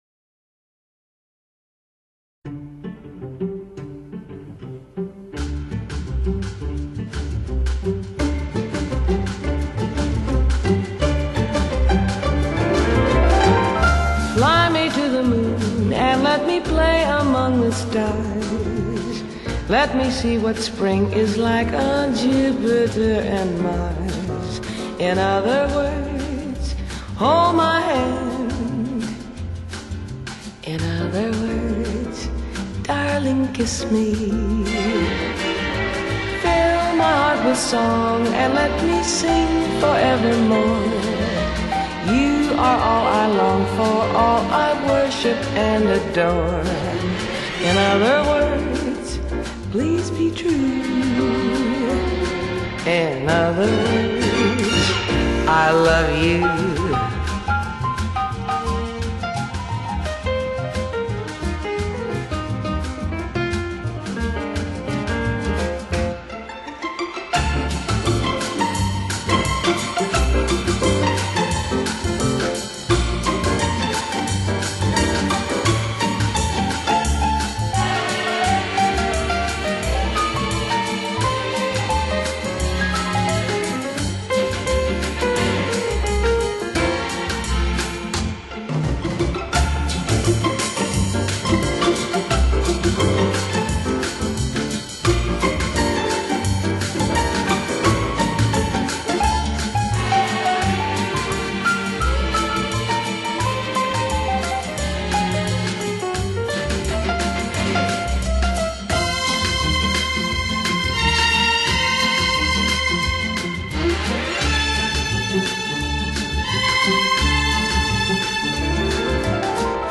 Jazz, Vocal